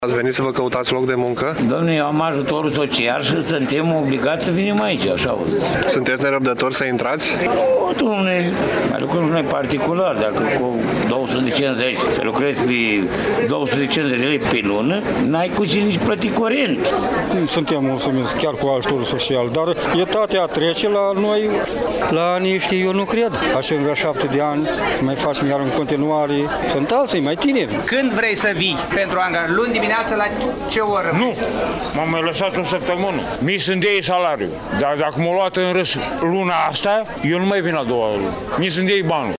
20-apr-ora-16-Vox-Vaslui-bursa.mp3